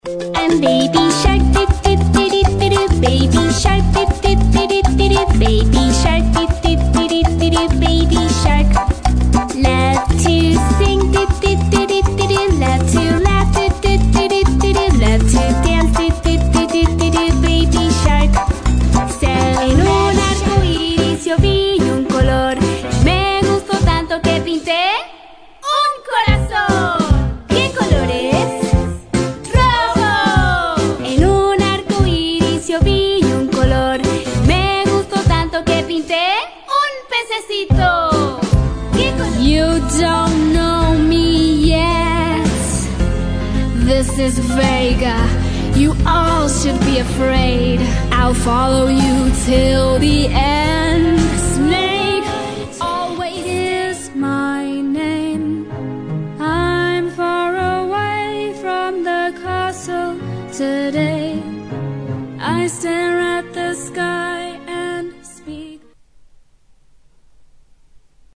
英语配音美式英语配音
美式英语 女声 唱歌 童谣儿童歌曲 积极向上|时尚活力|亲切甜美